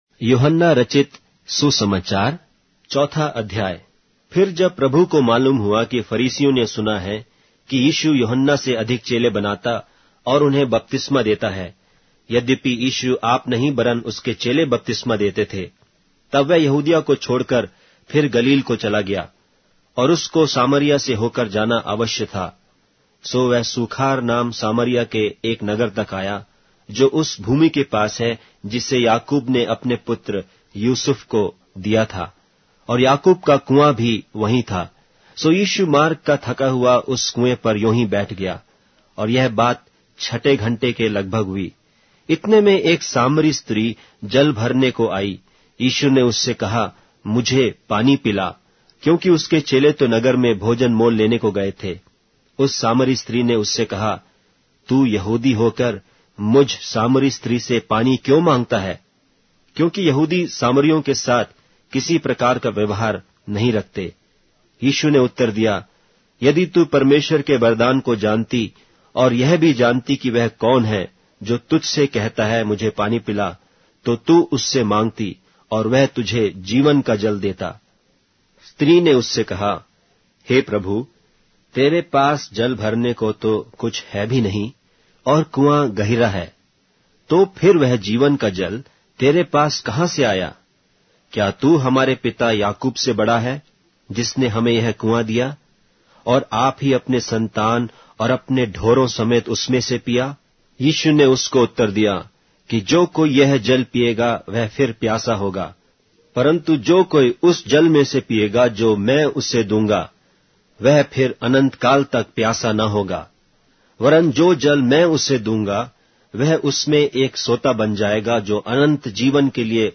Hindi Audio Bible - John 13 in Irvor bible version